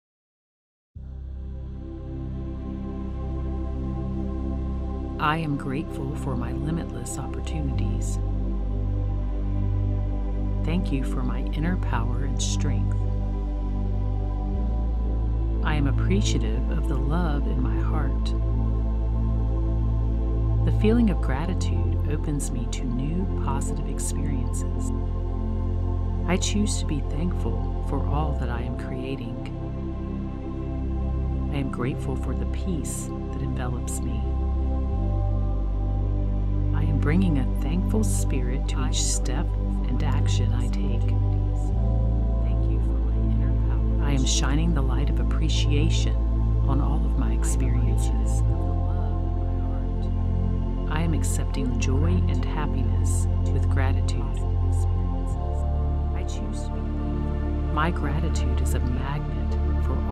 This 8 Hour meditation has over 10,000 affirmation impressions (both subliminal and non-subliminal) to program your mind and build your belief in manifesting abundance through gratitude!
The dual-induction (delivered independently to both ears) affirmations in this meditation will permeate your subconscious mind with powerful I AM affirmations that connect you with your inner strength and power to create and manifest a life you love! It is combined with a 528 Hz binaural tone, known as the “Miracle Tone” to assist you in manifesting miracles and promoting a calm and confident state.